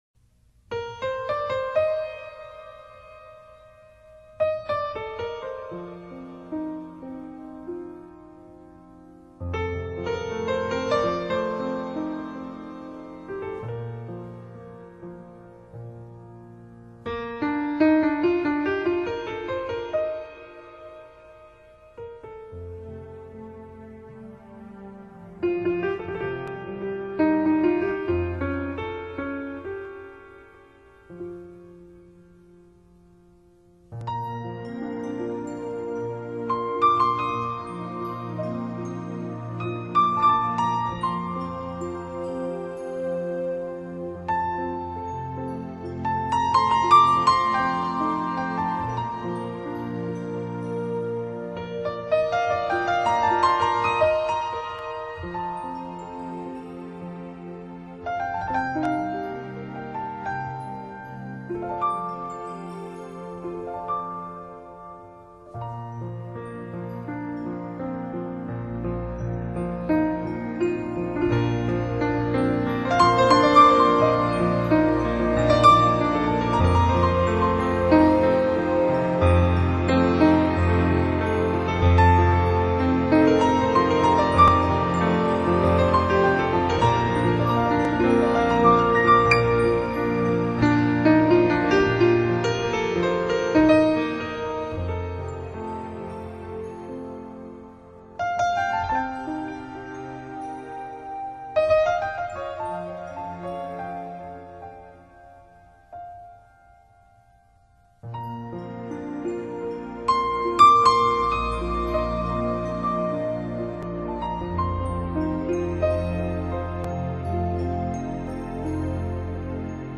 그의 날아갈 듯한 가벼운 피아노 선율은